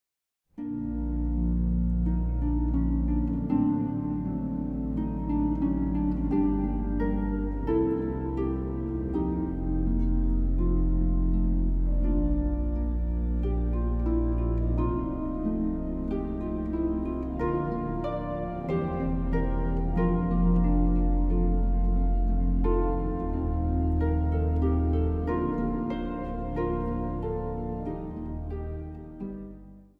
harp
orgel.
Zang | Jongerenkoor